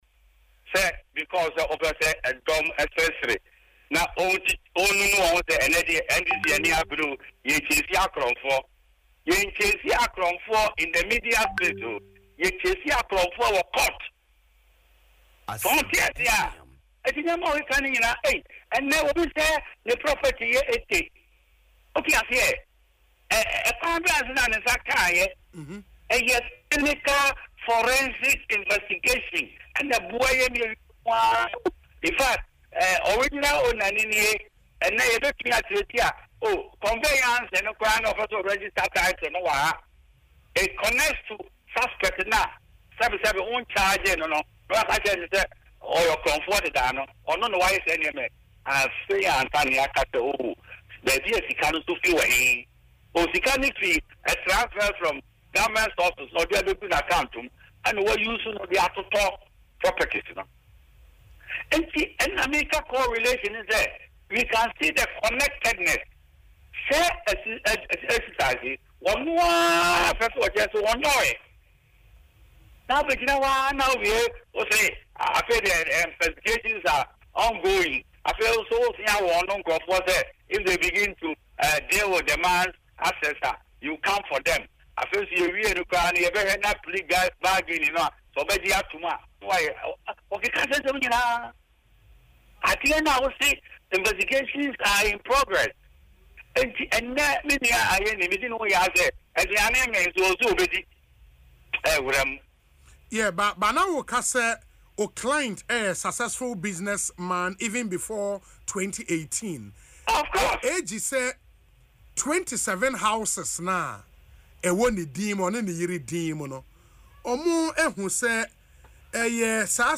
Speaking on Asempa FM’s Ekosii Sen, Atta Akyea argued that Adu Boahene was a businessman before his appointment in 2018 and should not be condemned without proper investigations.